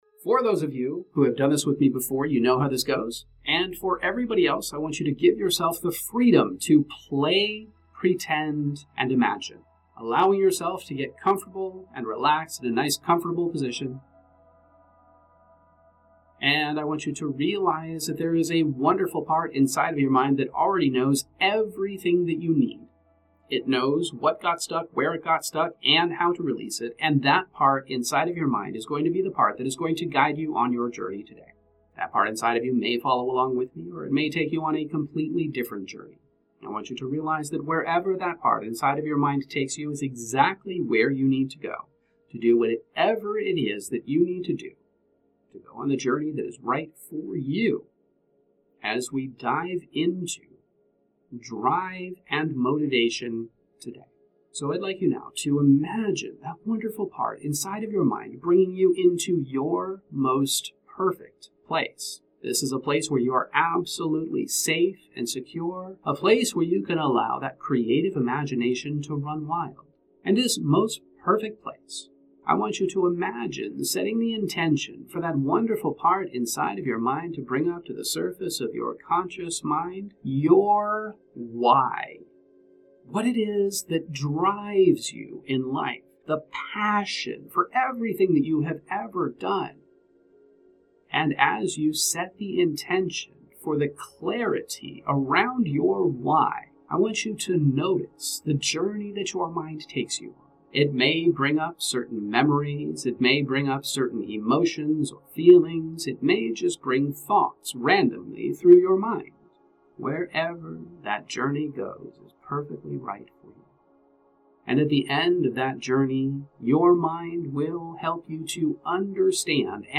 Emotional Optimization™ Meditations